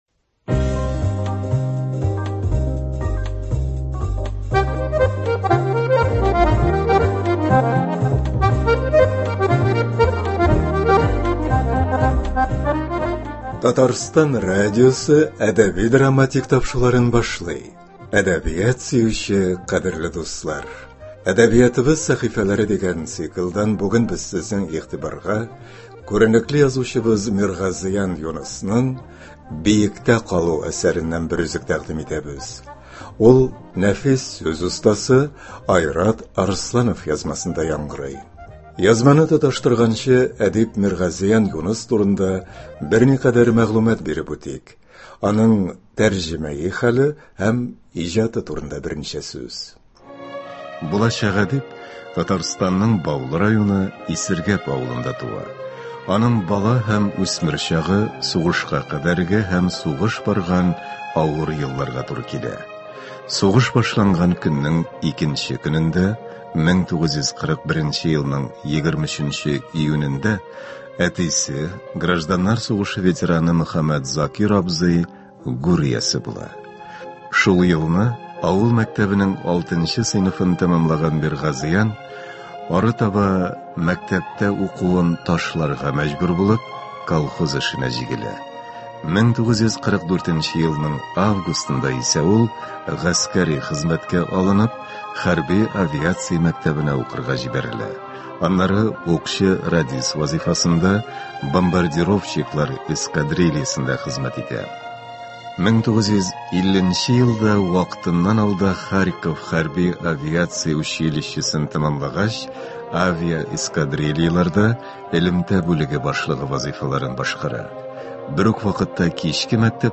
нәфис сүз остасы